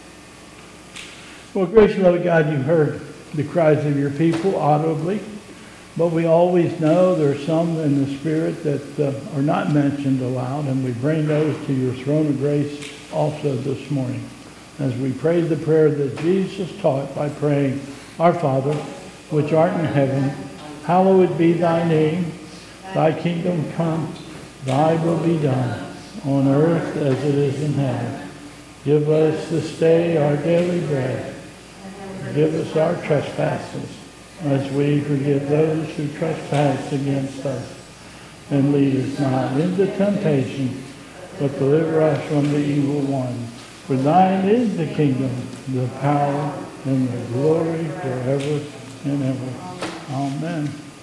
2022 Bethel Covid Time Service
Prayer Concerns
Pastoral prayer...and Lord's Prayer